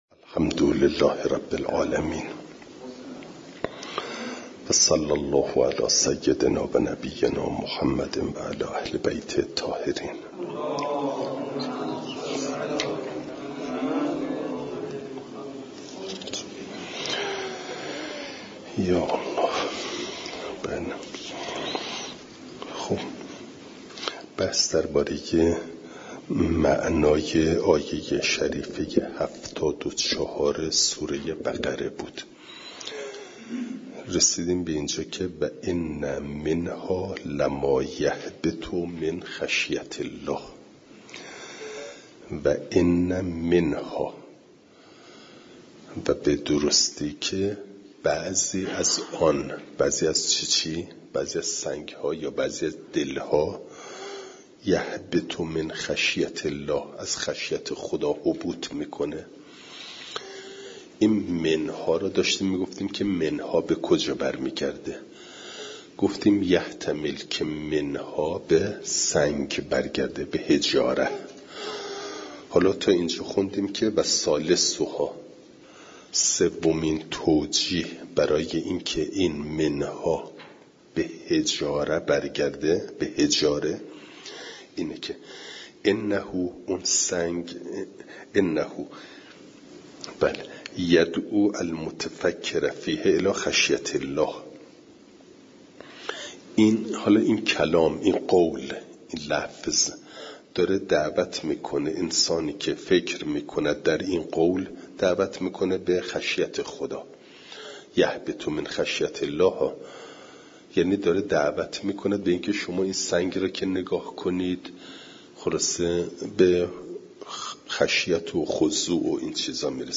فایل صوتی جلسه هشتاد و سوم درس تفسیر مجمع البیان